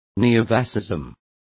Complete with pronunciation of the translation of neofascisms.